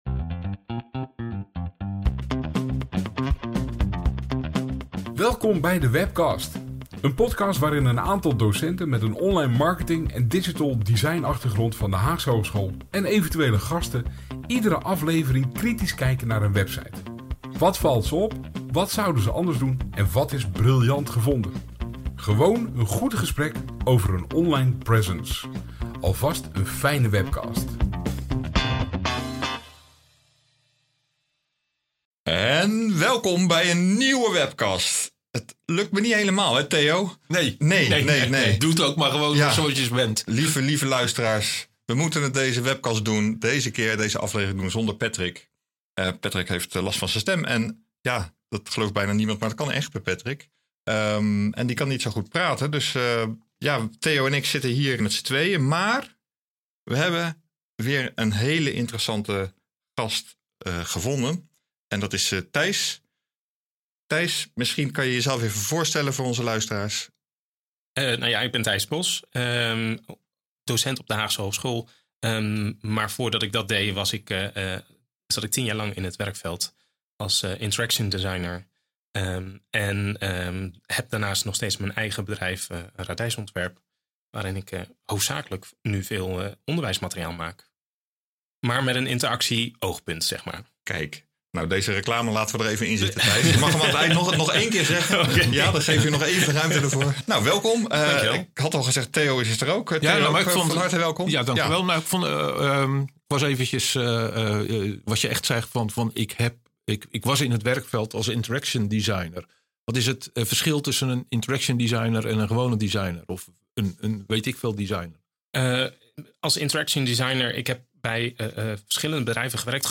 Gewoon een goed gesprek over een online presence.